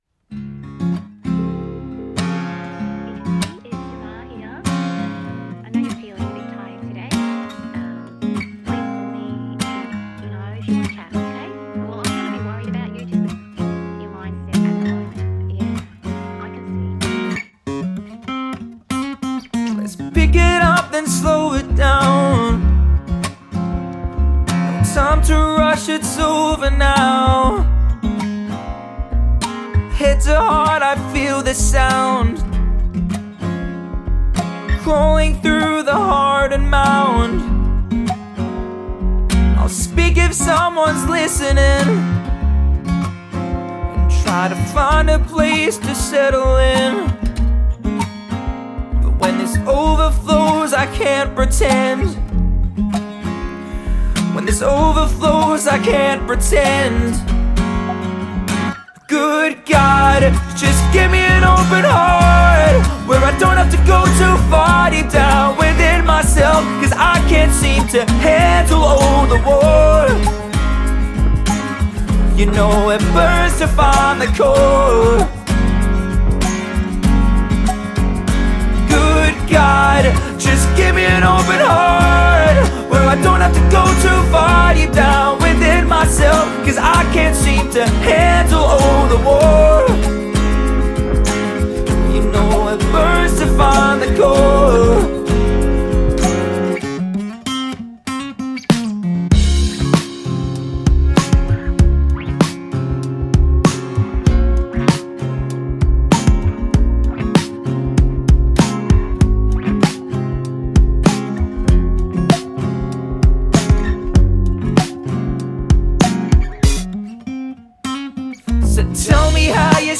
Dual Vocals | Looping | DJ